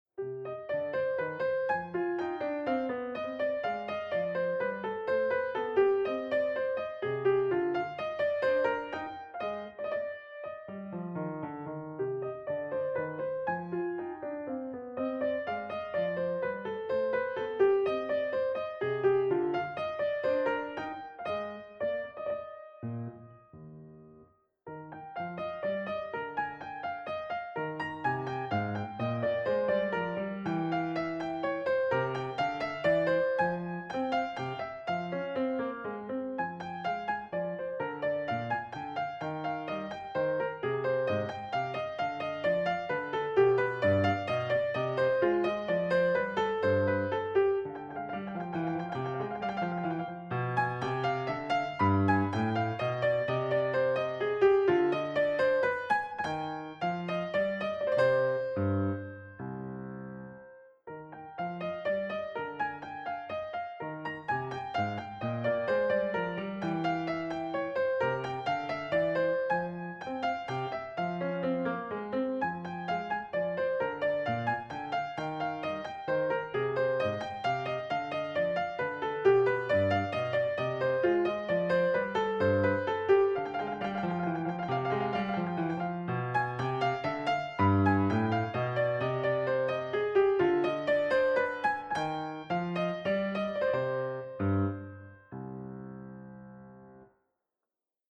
Enregistrement d'un récital de piano